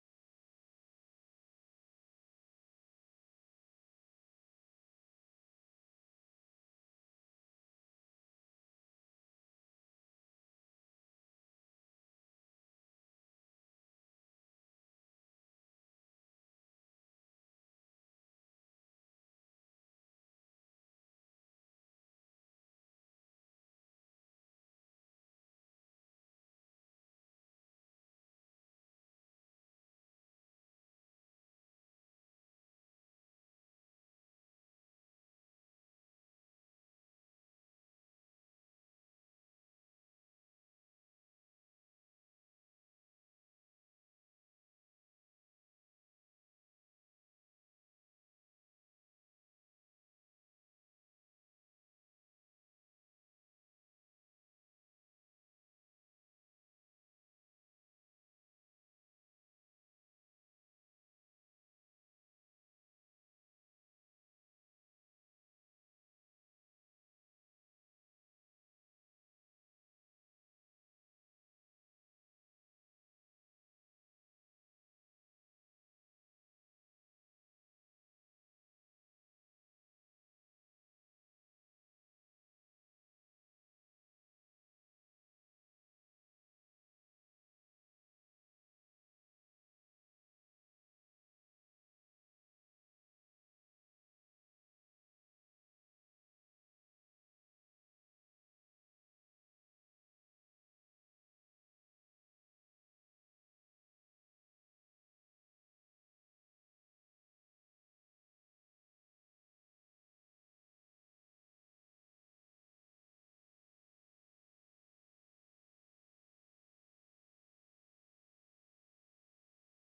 Passage: Luke 24:13-35 Service Type: Sunday Morning